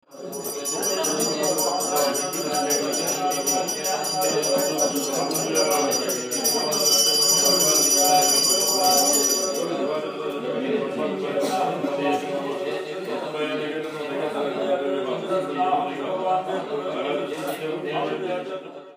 In einem weiteren Kloster unweit des Museums beobachten wir mit großem Staunen Mönche und Gläubge. Mehrere Mönche sitzen auf Bänken und beten jeder laut vor sich hin.
Tsetserleg.mp3